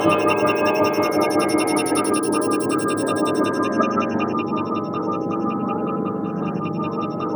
Index of /90_sSampleCDs/Spectrasonic Distorted Reality 2/Partition D/07 SCI-FI 1
TWINKLCLOK.wav